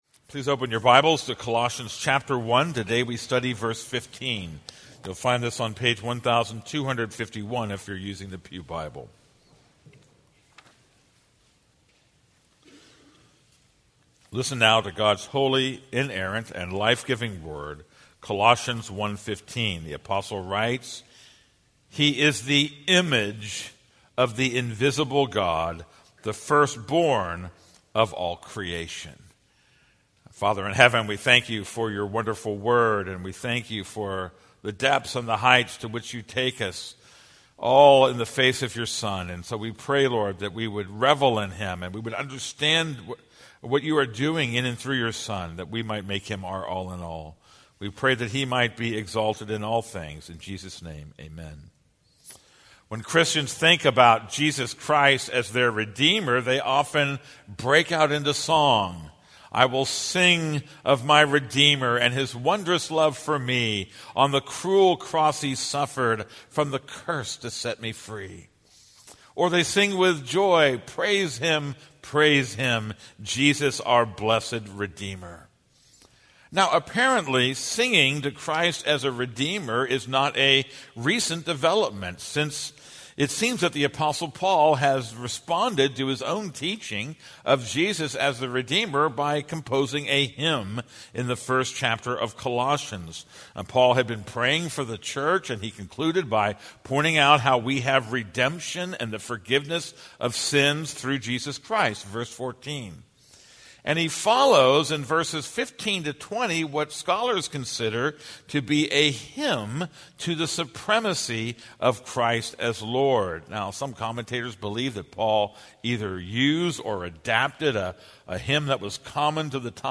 This is a sermon on Colossians 1:15.